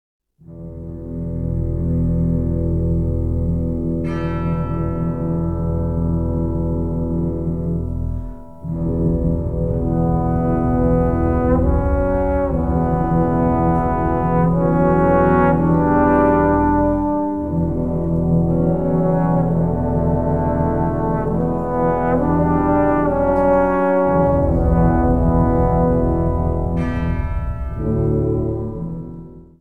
Unterkategorie Suite
Besetzung Ha (Blasorchester)